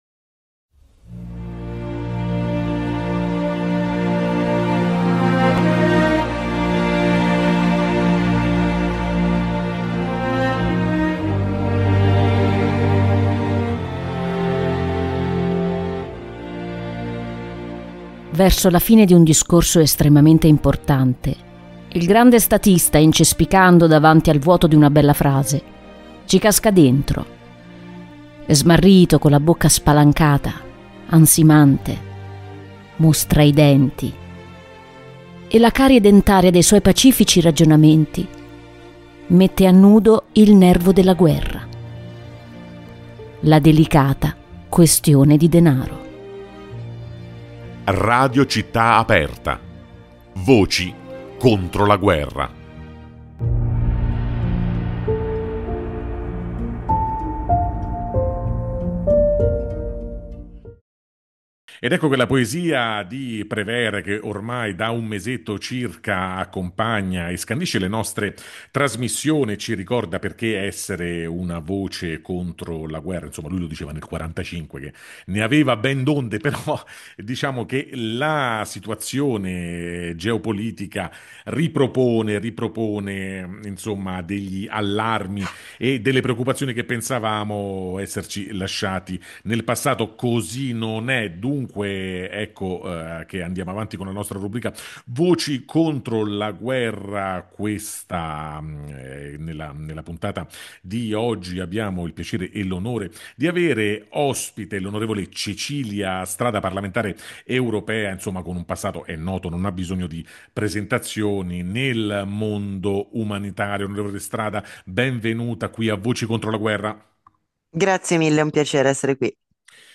In questa puntata la parlamentare europea e attivista Cecilia Strada ci parla di come l’Europa stia attraversando un periodo di crisi nel ripensare il suo ruolo attraverso i conflitti che funestano i confini del Vecchio Continente, dalla guerra Russo-Ucraina (che ha recentemente spinto la UE verso una politica di riarmo) alla contrapposizione israelo-palestinese. Ma per l’On. Strada è proprio questo il momento per essere una “Voce contro la Guerra”, essere pervicacemente attaccati alla Pace e opporre una narrazione diversa rispetto a coloro che danno la guerra come una verità ineluttabile.